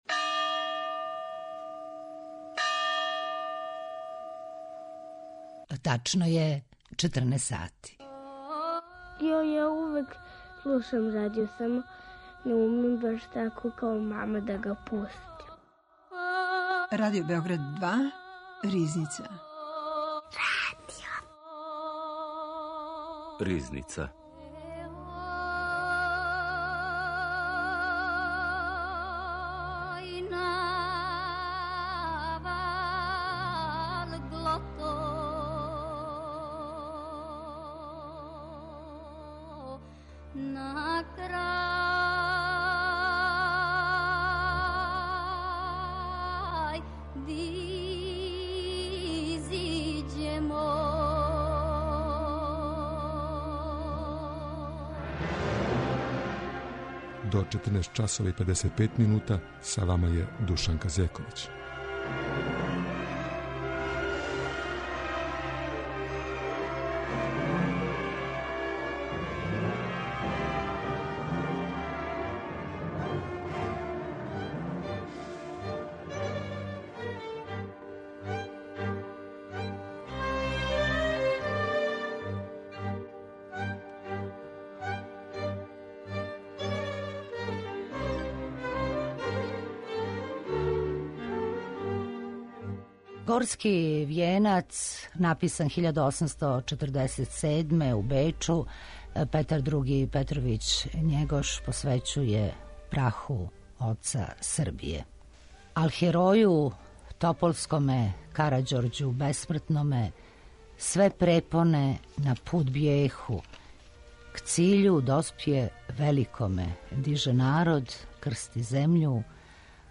новинар и публициста.